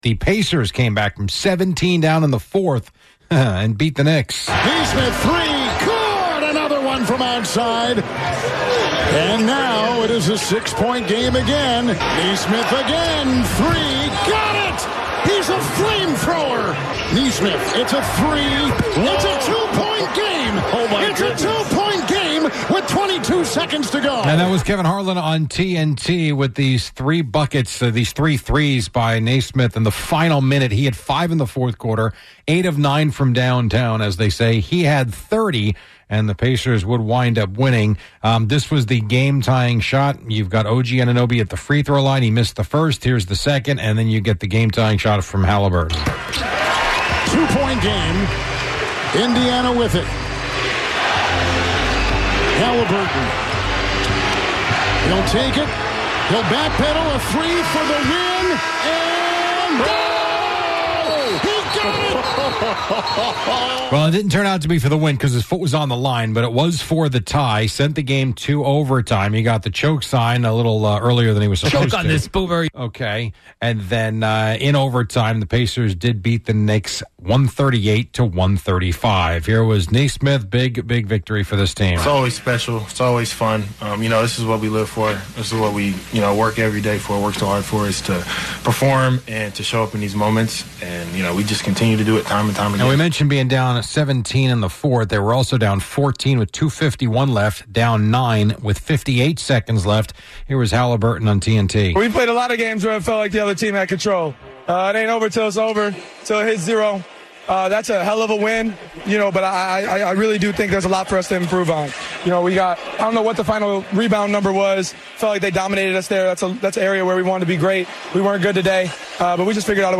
We hear Kevin Harlan on the call as the Pacers come back to beat the Knicks. The Mets won in Boston as Brett Baty had 3 RBIs. Jasson Dominguez had a walk-off HR as the Yankees beat the Rangers.